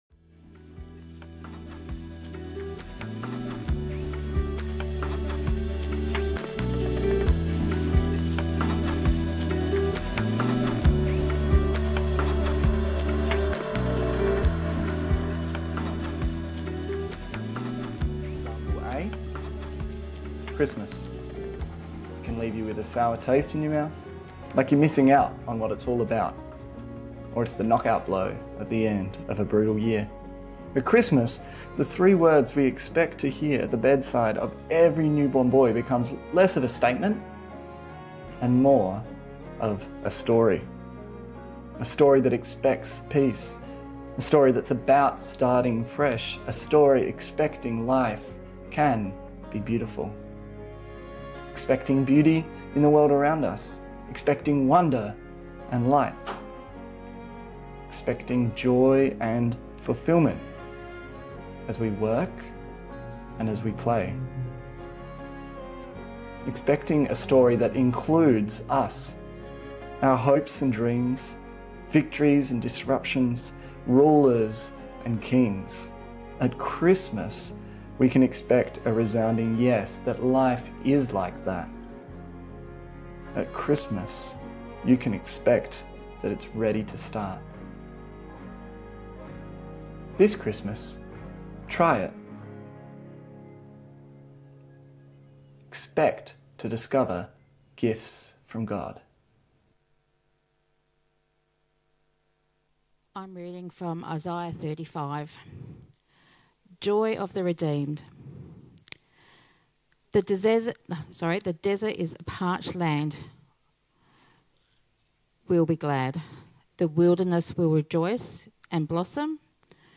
Enfield Baptist Church